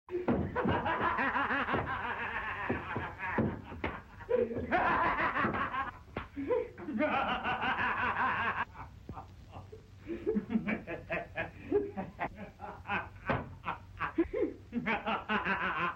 Звуки со смехом злого ученого, маньяка и его лаборатория для монтажа видео в mp3 формате.
2. Смех, когда у ученого все получилось